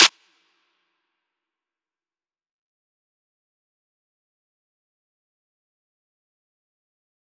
DMV3_Snare 4.wav